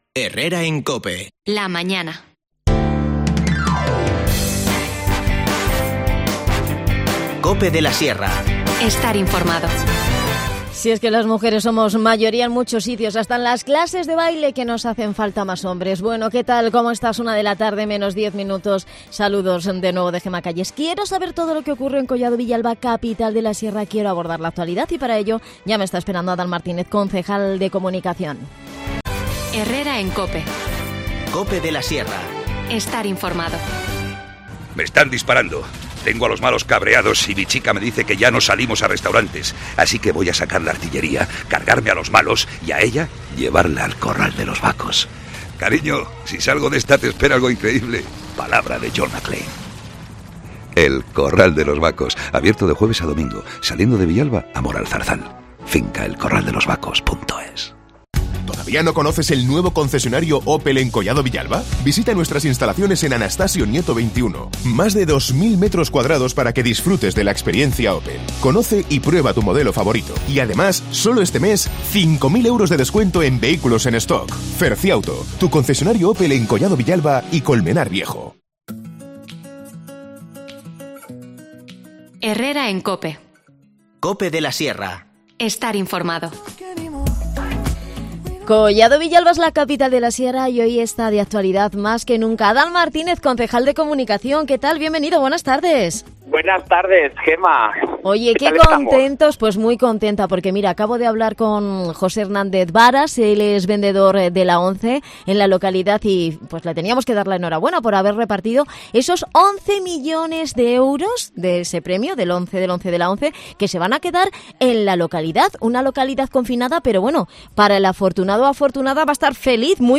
AUDIO: Adan Martínez, concejal de Comunicación en Collado Villalba, repasa la actualidad en Capital de la Sierra.